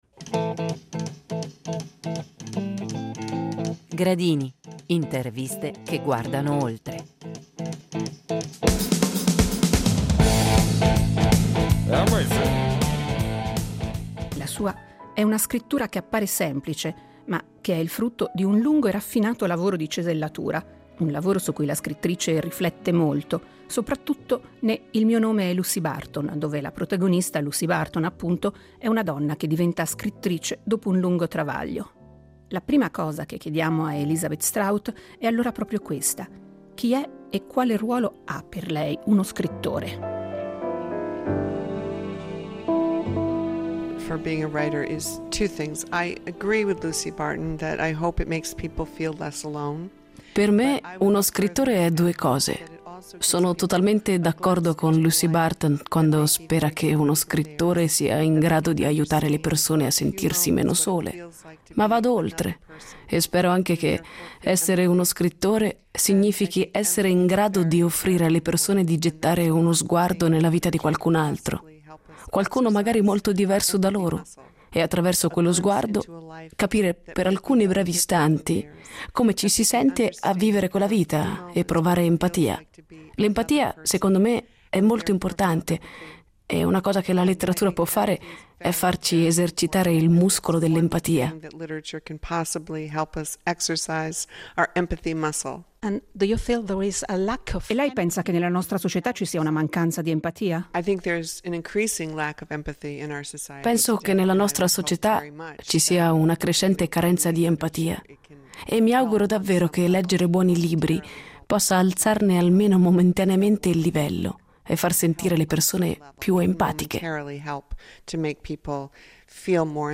Voci d’Autore nella memoria della nostra radio
Elisabeth Strout è la protagonista della nuova puntata di Gradini – Interviste che vanno oltre , incontri con grandi scrittori e scrittrici che costituiscono la memoria della Rete Due .